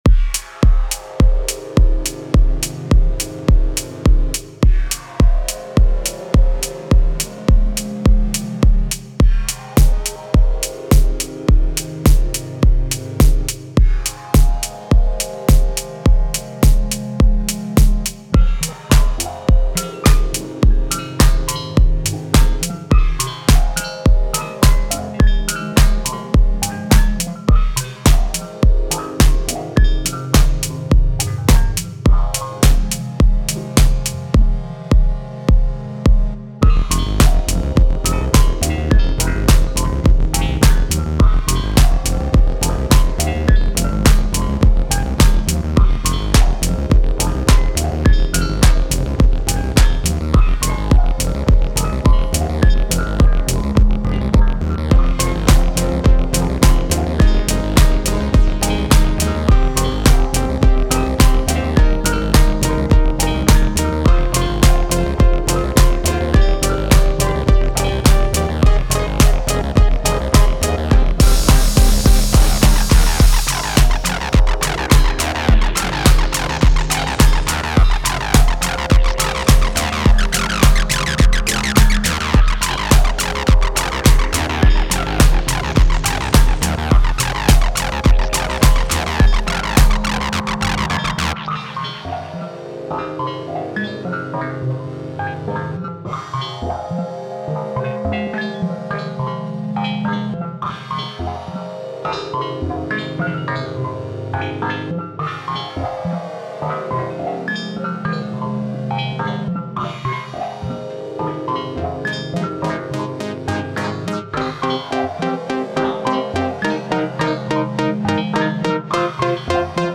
Letting TD-3-MO have it.